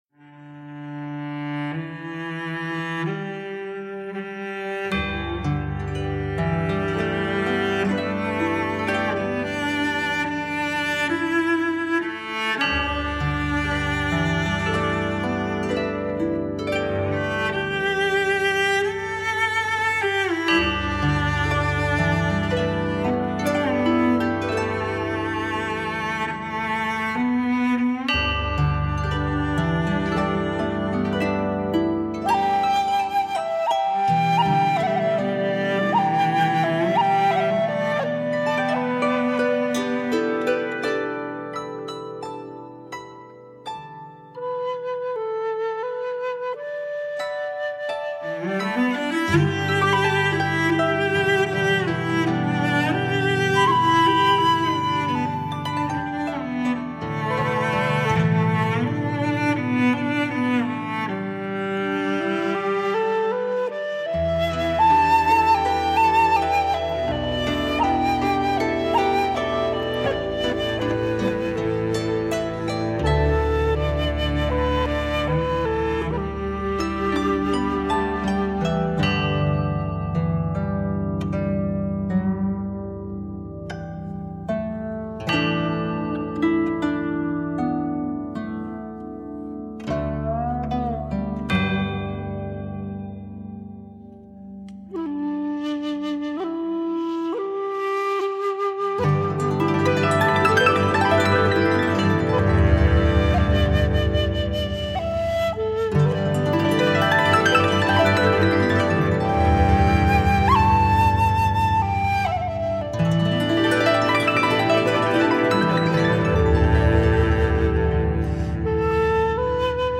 与大提琴、箫及编钟